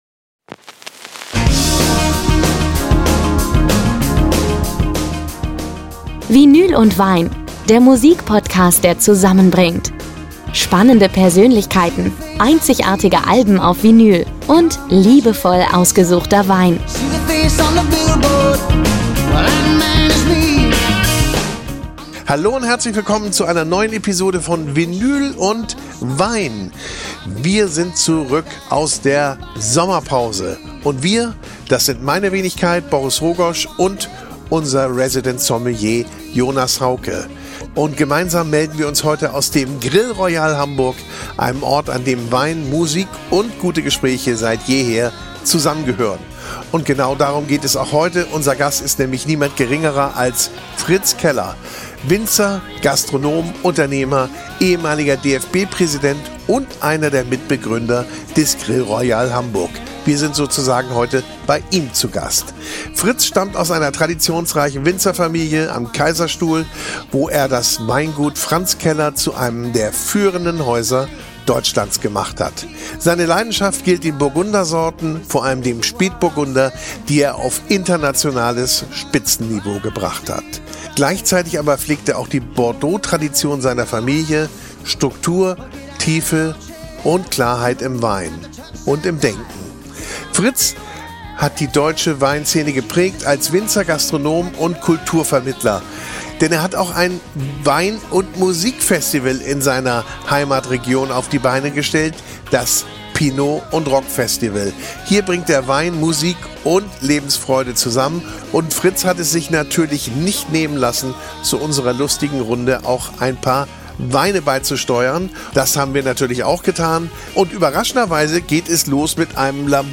Gemeinsam öffnen sie besondere Flaschen, legen die von Fritz Keller handverlesenen Platten auf und sprechen über das, was Wein, Musik und Leben miteinander verbindet.
Zwischen den Gläsern erzählt Fritz Keller mit Witz und Wärme über seine Philosophie des Genusses, über Nachhaltigkeit und Respekt im Umgang mit Mensch und Natur. Und er spricht offen über die aktuellen Probleme im Weinbau – vom Klimawandel über den Fachkräftemangel bis hin zur Bürokratie, die vielen Betrieben zu schaffen macht.